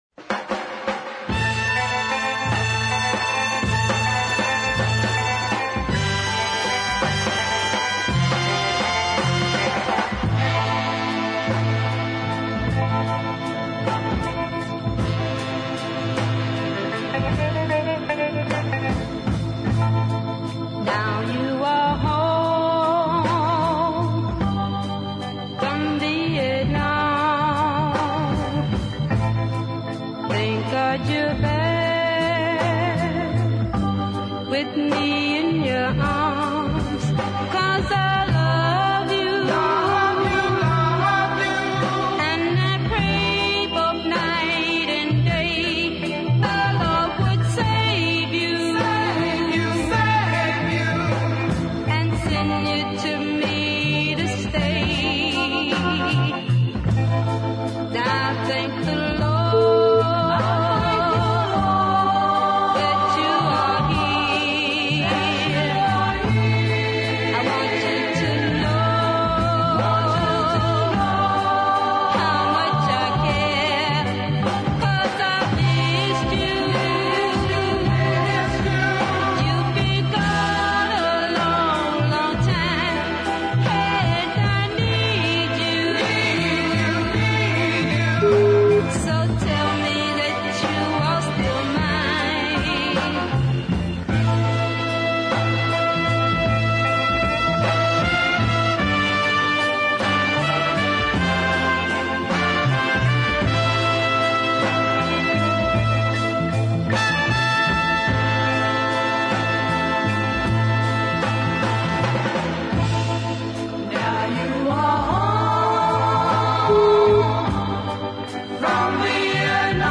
a weepy ballad of considerable presence